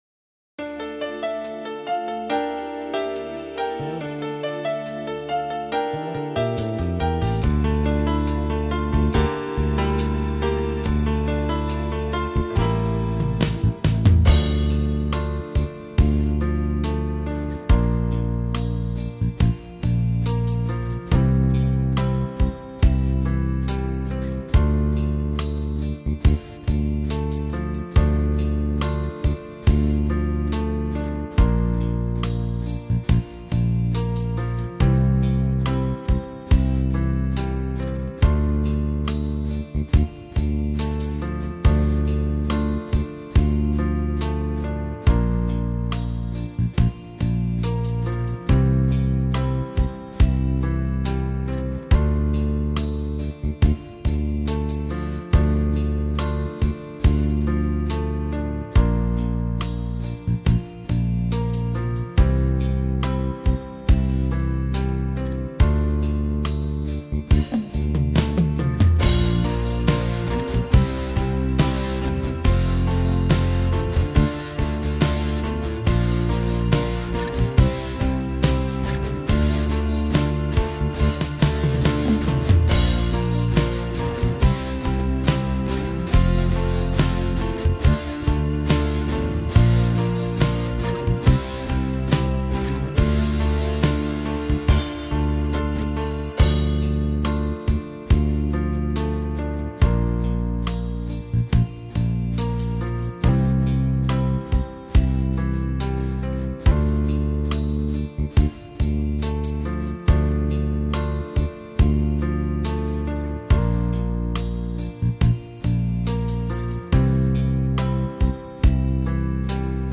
Скачать христианскую музыку и фонограммы.